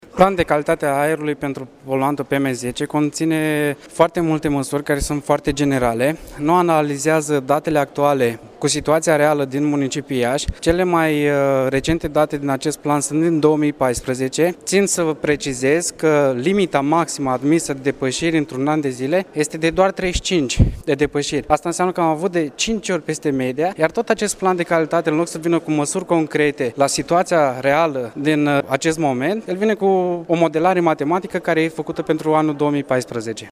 Etienne Ignat, reprezentat al PNL, a precizat că datele care au fost luate în considerare în cadrul planului privind calitatea aerului nu mai sunt de actualitate: